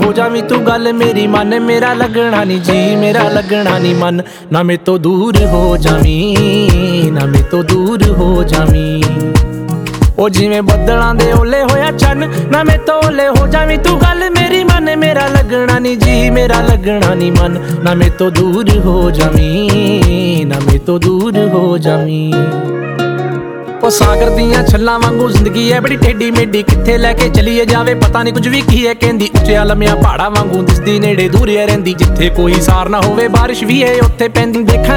Жанр: Инди / Местная инди-музыка